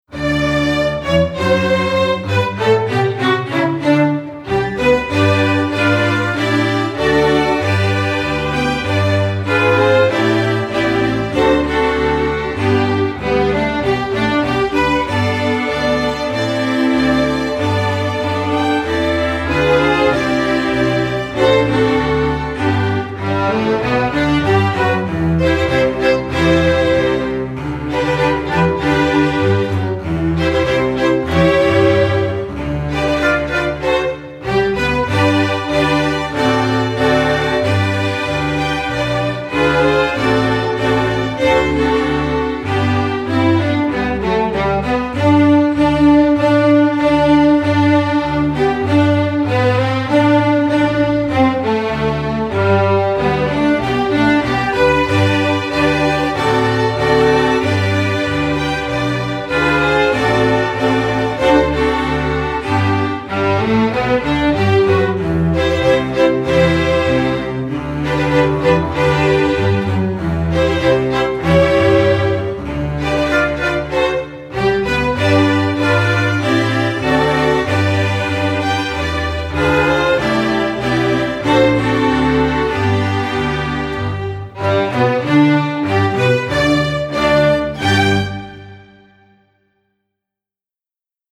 Instrumentation: string orchestra
hymn, patriotic, festival